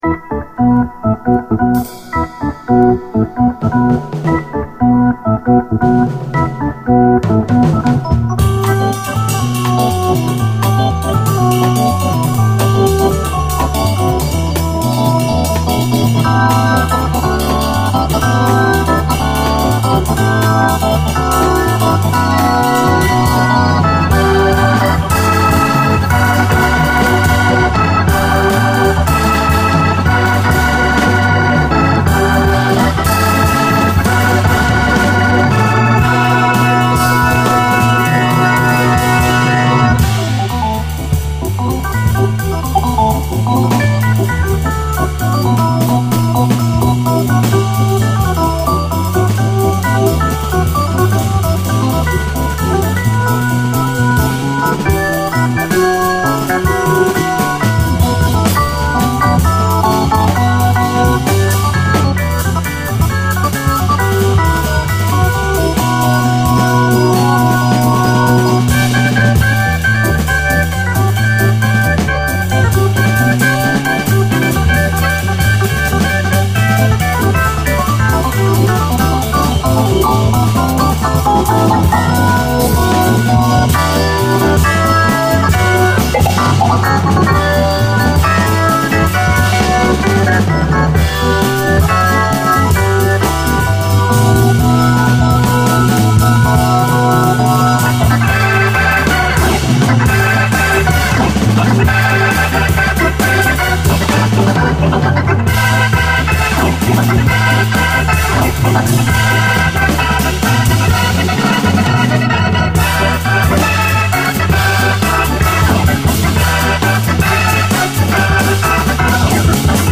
JAZZ FUNK / SOUL JAZZ, JAZZ
フランスの女流オルガン奏者のレアな一枚！
気高さをまき散らしつつ流麗に疾走、圧倒するキラー・トラック！